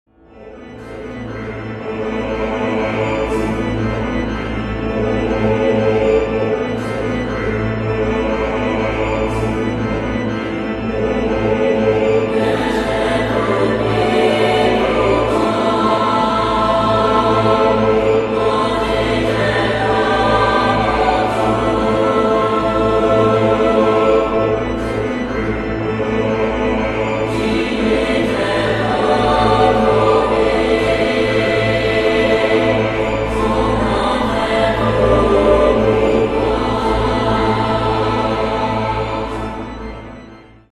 • Качество: 192, Stereo
инструментальные
OST
хор
орган